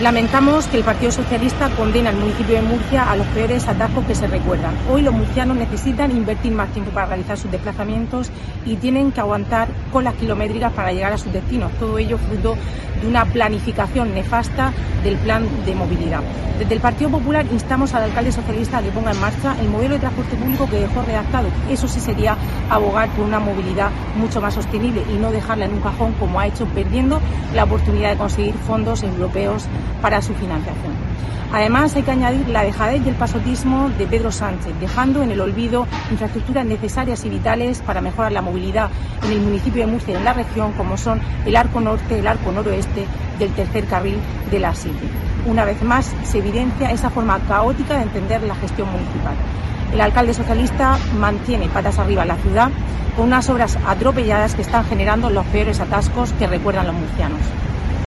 Rebeca Pérez, portavoz del PP en el Ayuntamiento de Murcia